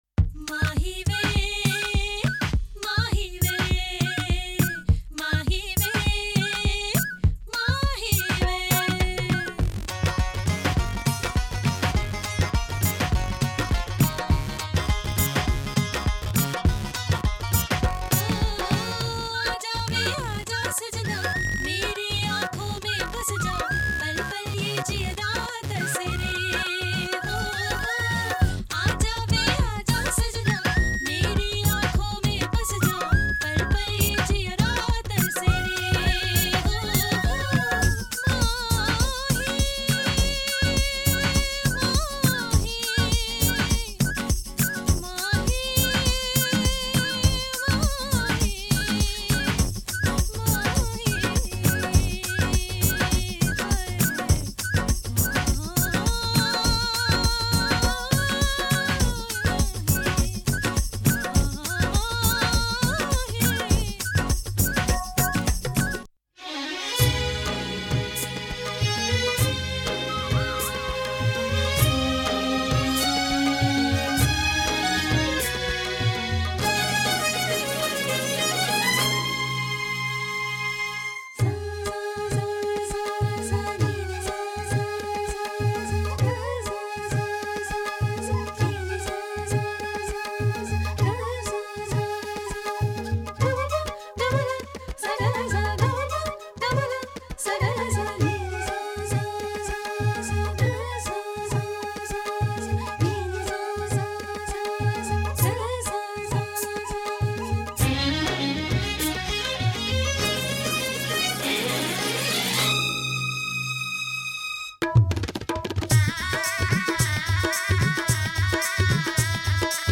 库已组装成各种节奏和音调的套件。
01.印度小提琴和中提琴
02. Bansuri（印度长笛）
03. Dholak乐团
04. Tabla乐团/ Pakhawaj
07. Sarangi
11.锡塔乐团
14. Shehnai
16.印度合唱团（男，女
该库的处理过程中未使用任何音频插件，因此声音保留了经典模拟设备的保真度和温暖度。
高处理循环包含湿式和干式版本，因此您可以选择是处理处理循环还是未处理循环。